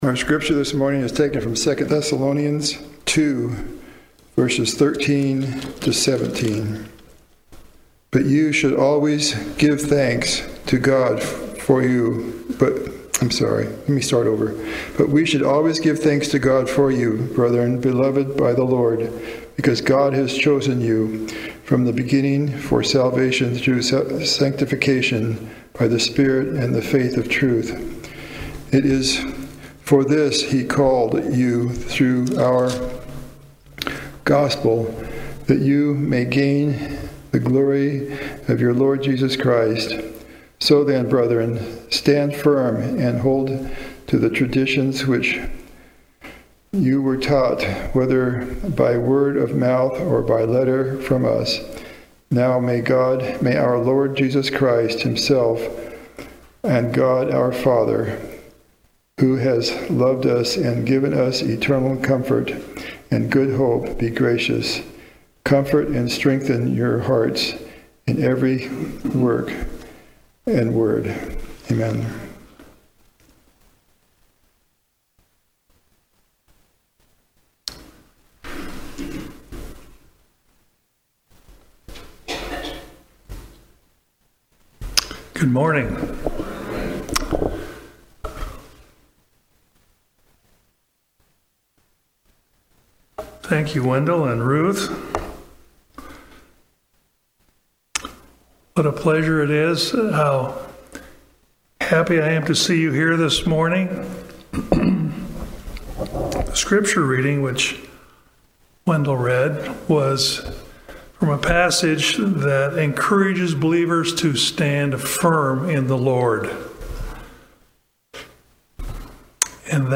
Seventh-day Adventist Church, Sutherlin Oregon
Sermons and Talks 2025